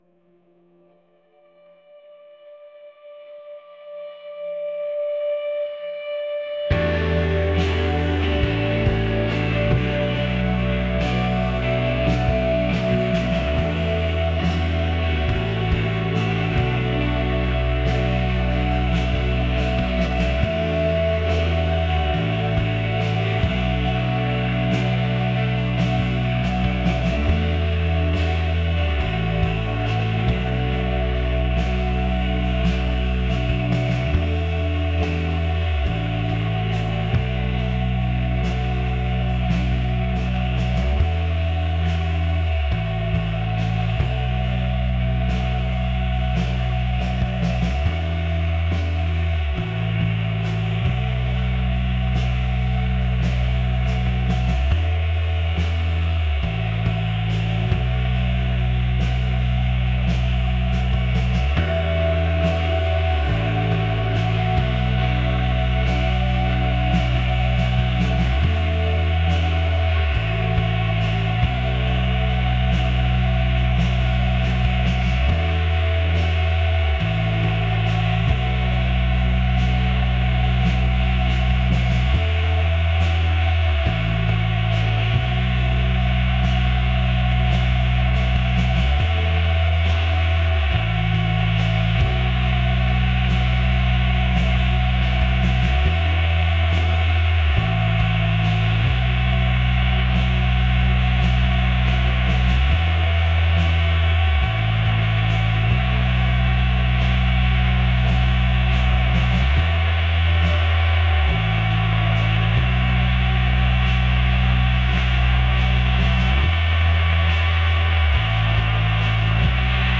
pop | ethereal | dreamy